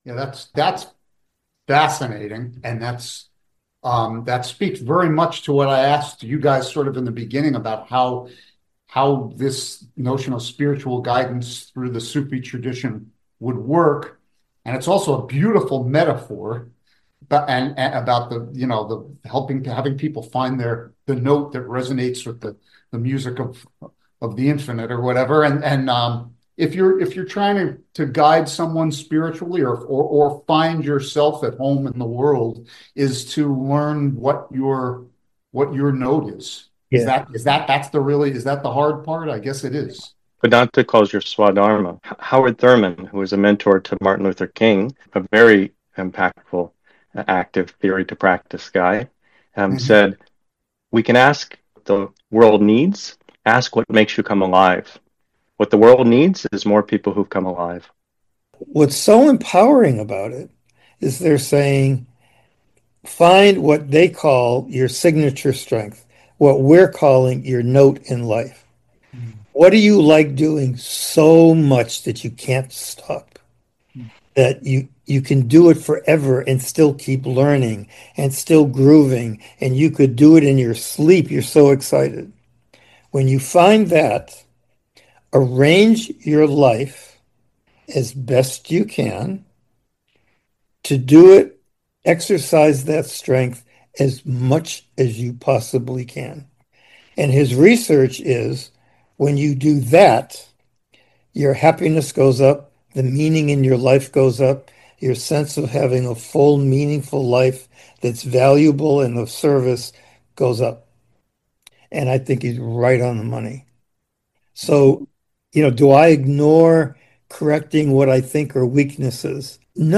Part 2 Theoretical Activism, An Exploration of Wu Wei: Practical Application of Philosophy, A Panel Discussion : Amber Light International: An Inclusive, Interdisciplinary Approach to Spirituality Without Dogma or Religion